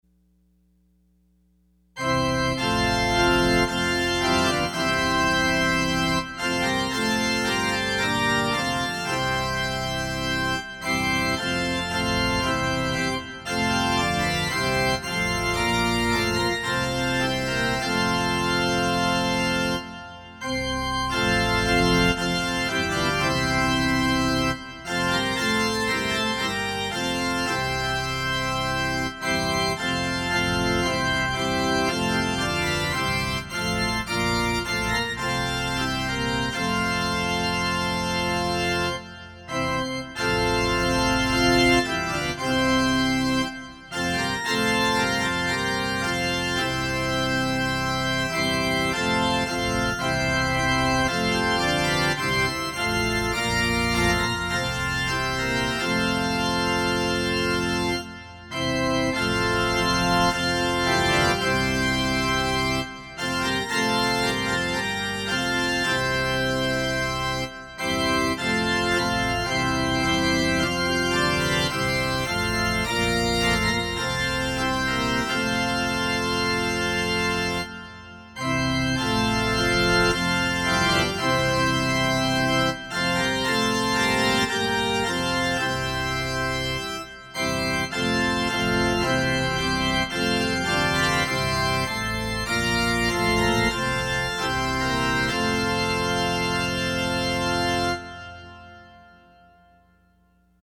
Opening Hymn  –  #524  I love thy kingdom, Lord